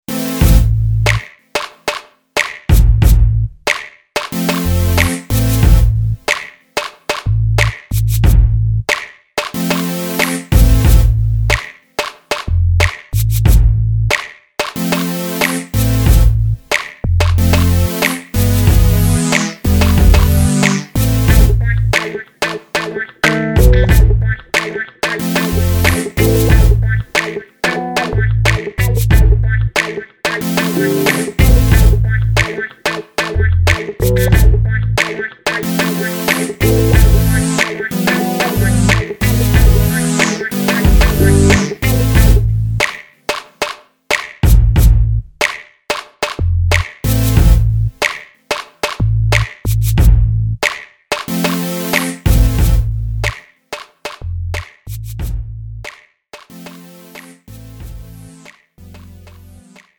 장르 pop 구분 Premium MR
Premium MR은 프로 무대, 웨딩, 이벤트에 최적화된 고급 반주입니다.